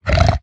latest / assets / minecraft / sounds / mob / hoglin / idle2.ogg